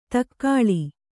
♪ takkāḷi